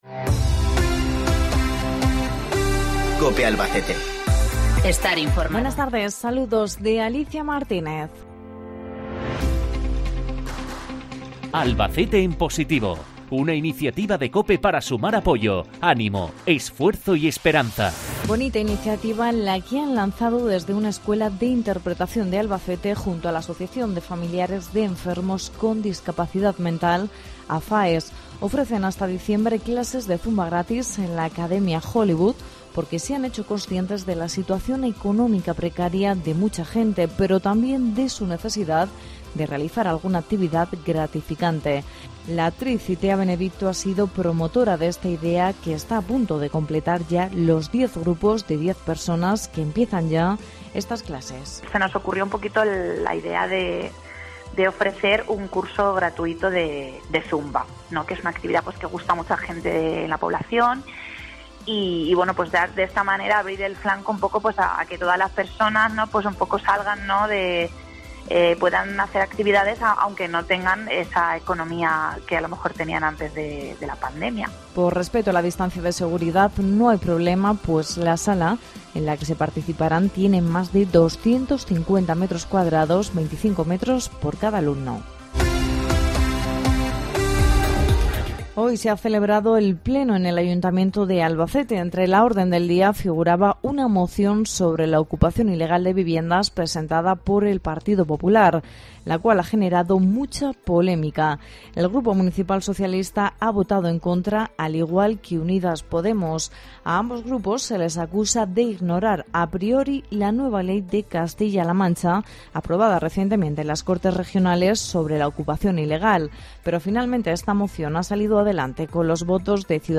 INFORMATIVO LOCAL 14.20